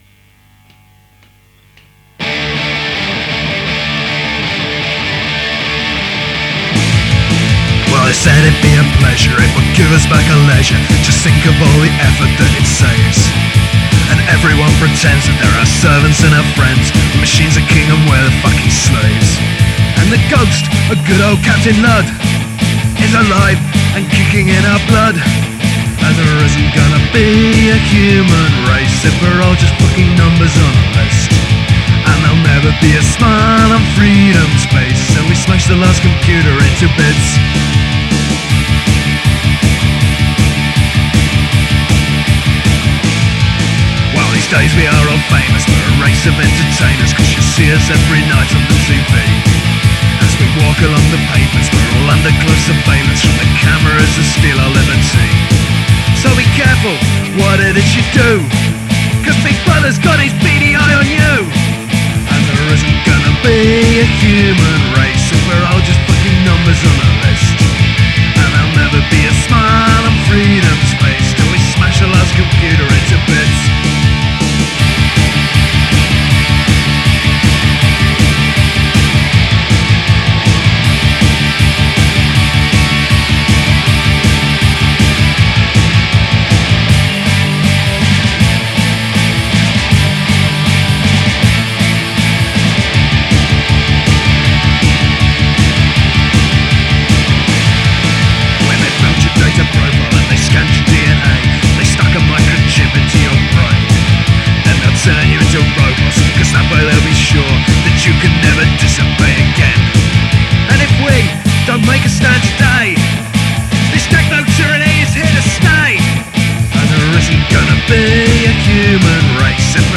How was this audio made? DIY punk tracks